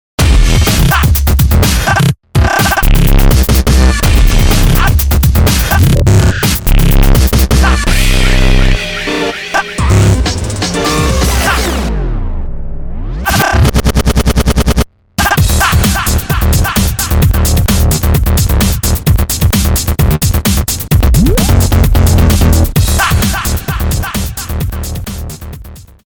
Loud.